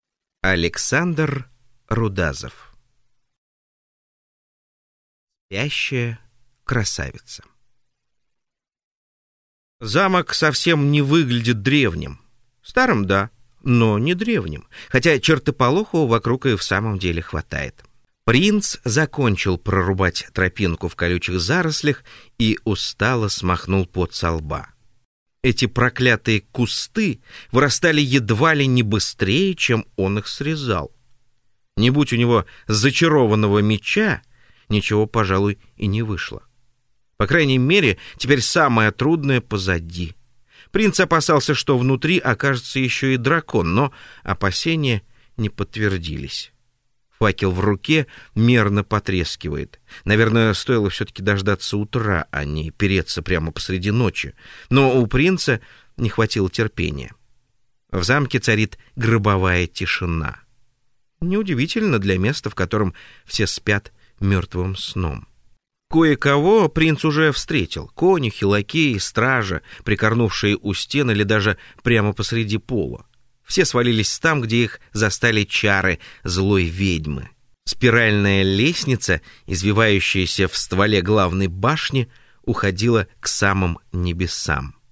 Аудиокнига Спящая красавица | Библиотека аудиокниг
Прослушать и бесплатно скачать фрагмент аудиокниги